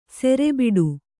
♪ sere biḍu